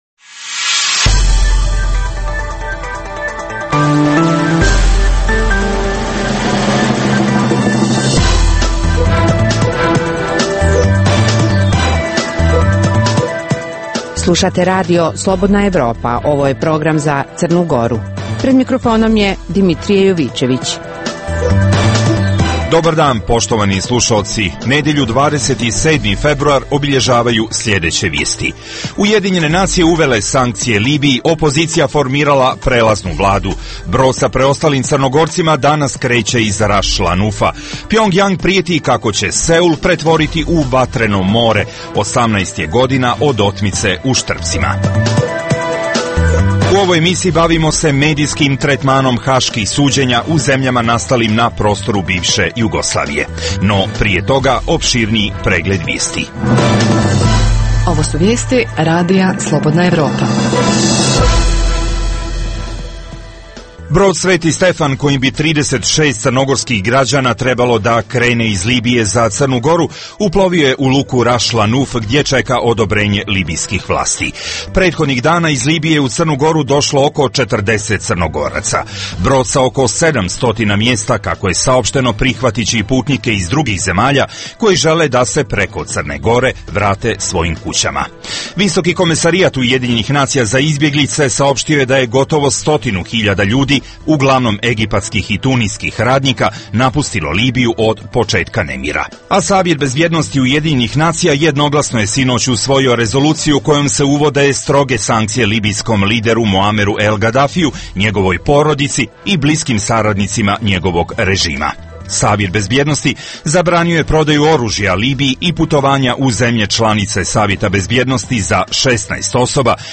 Emisija namijenjena slušaocima u Crnoj Gori. Sadrži lokalne, regionalne i vijesti iz svijeta, rezime sedmice, intervju "Crna Gora i region", tematske priloge o aktuelnim dešavanjima u Crnoj Gori i temu iz regiona.